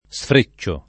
vai all'elenco alfabetico delle voci ingrandisci il carattere 100% rimpicciolisci il carattere stampa invia tramite posta elettronica codividi su Facebook sfrecciare v.; sfreccio [ S fr %©© o ], -ci — fut. sfreccerò [ S fre ©© er 0+ ]